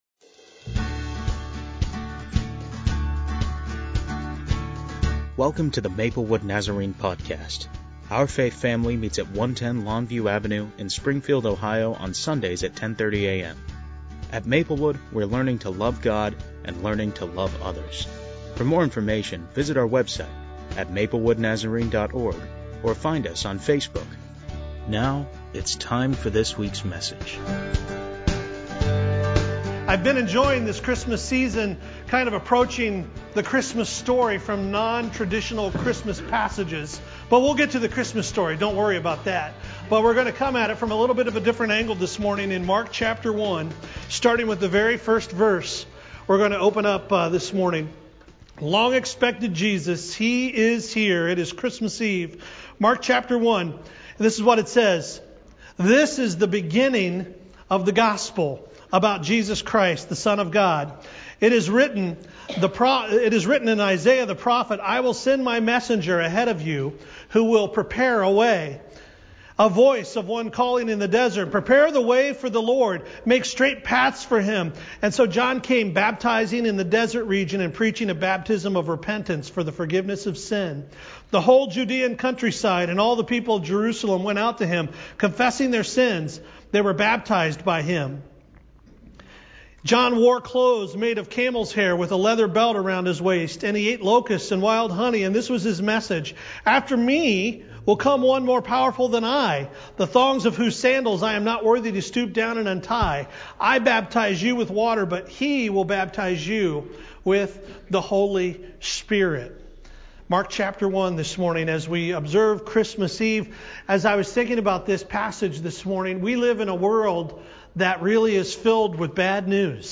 There's a lot of bad news in the world--but Christmas brings "good news!" At our Christmas Eve Service